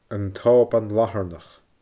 An t-Òban Latharnach /əN tɔːban LahəRNəx/